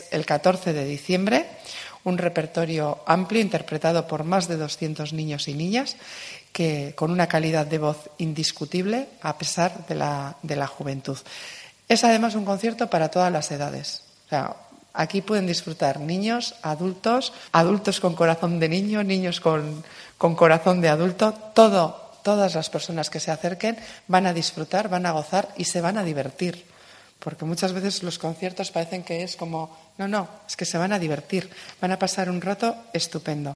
Lorea Bilbao, diputada de cultura de Bizkaia, presenta "Gabonetako Soinuak"